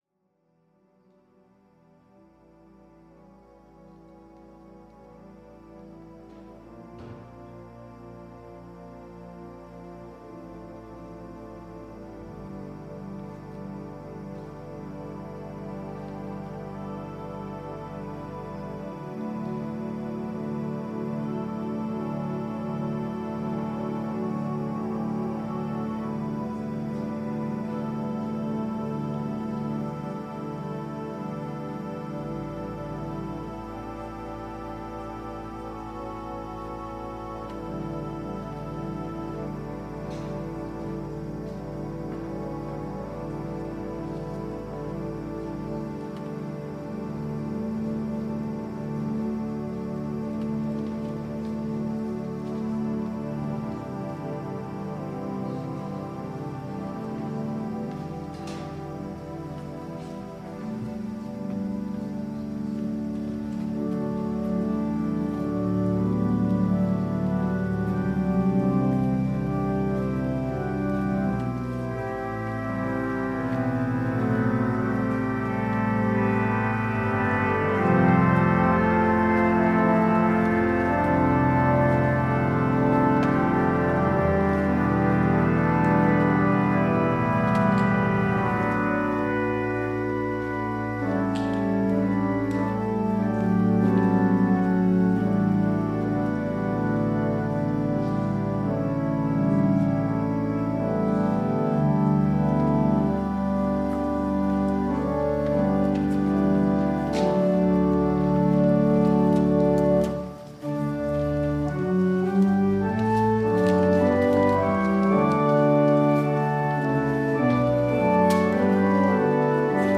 Worship and Sermon audio podcasts
WORSHIP - 4:00 p.m. Third after Epiphany